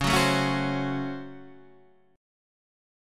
C# Major 7th Suspended 4th Sharp 5th